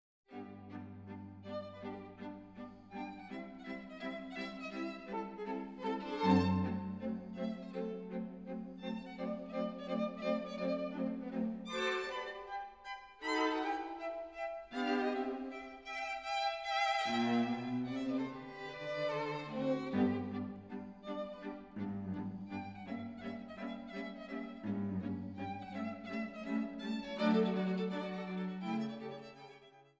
für zwei Violinen, Viola und Violoncello